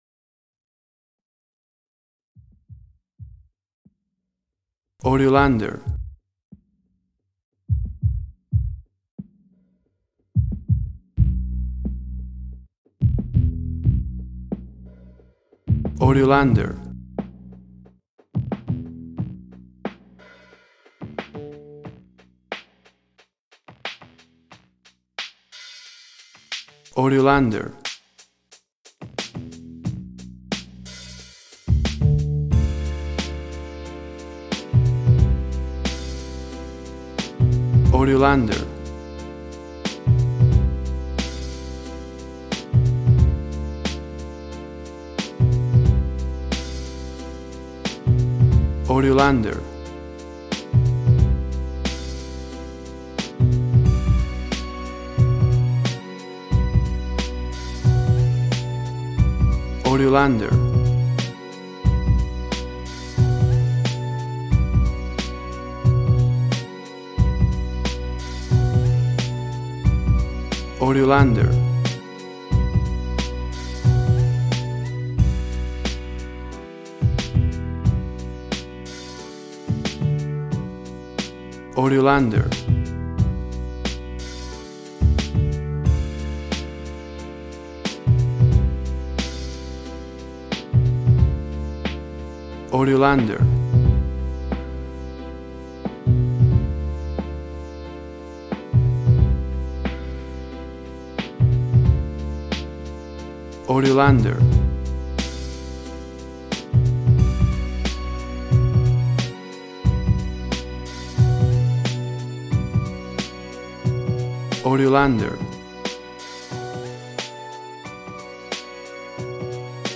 Tempo (BPM): 90